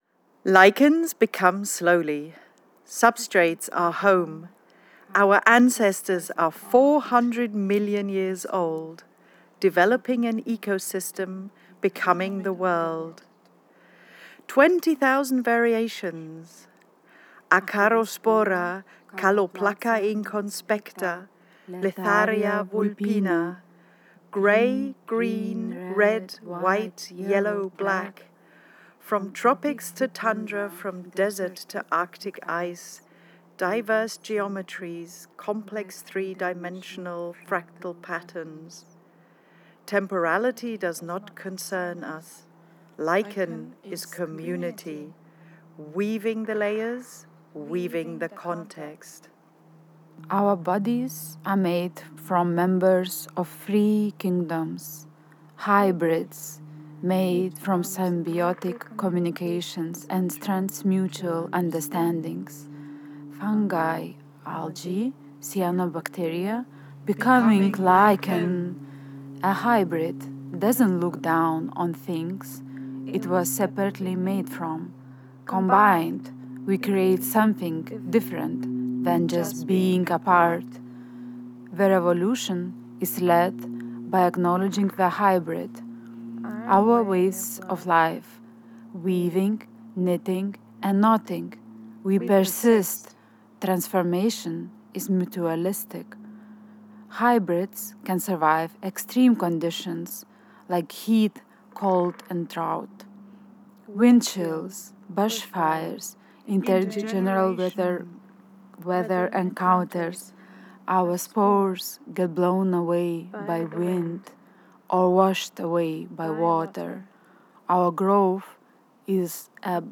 A performative reading of the collaboratively written manifesto Lichen Poetic Technology.
Speaking from the perspective of lichens, the reading entices human hybridity with the vegetal world and questions how processes of co-bodying can contribute to generative (human) futures and planetary symbiotic cohabitation.
Lichen-manifesto_Berlin-group-recording.wav